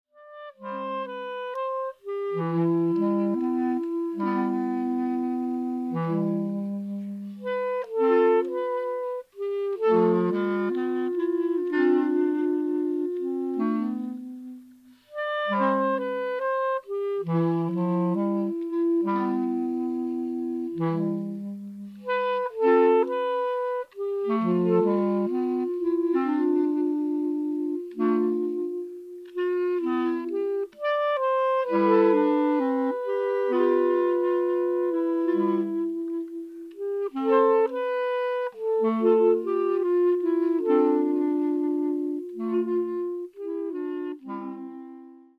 クラリネットだけでため息のような音楽を奏でる